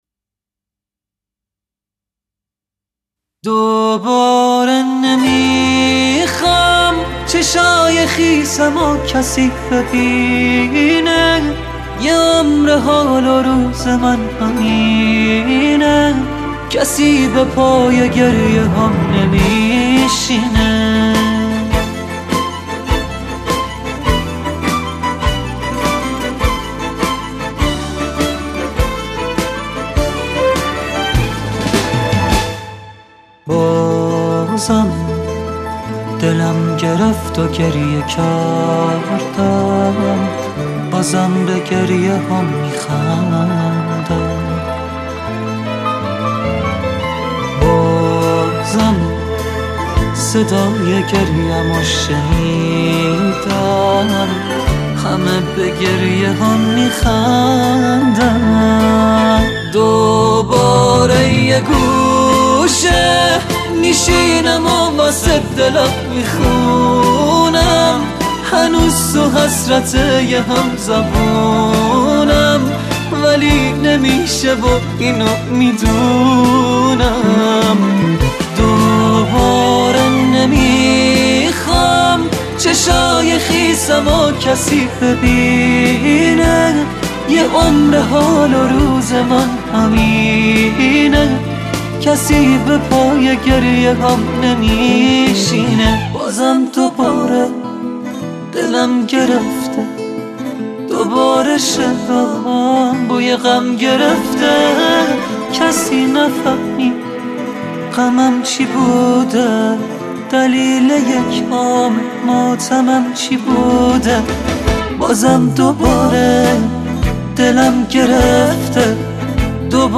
ژانر: پاپ